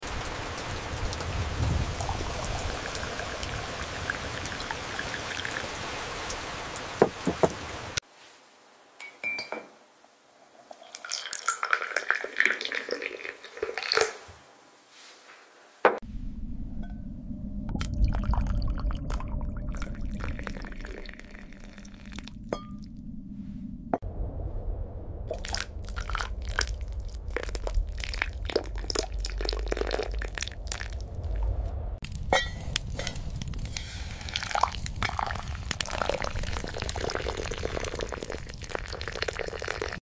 Follow For More Daily Asmr Sound Effects Free Download